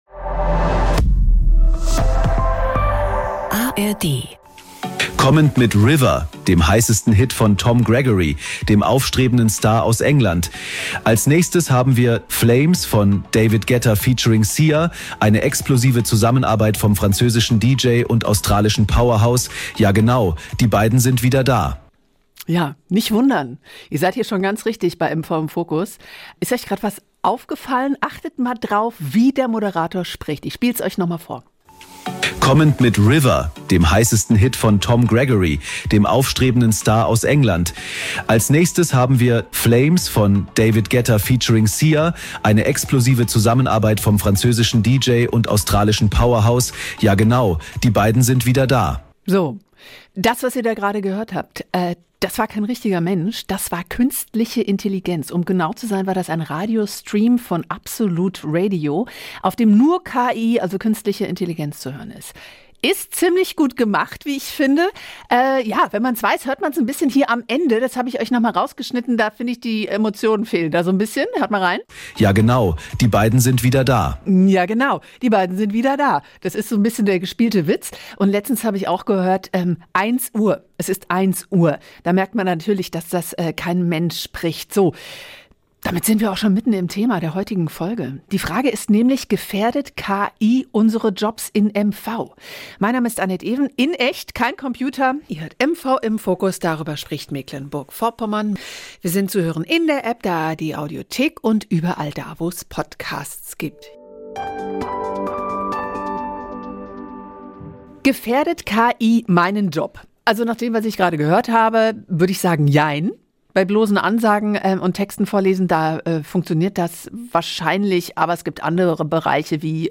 talken dazu wöchentlich im Wechsel mit Reporterinnen und Reportern aus den NDR MV Regionalstudios oder mit spannenden Gästen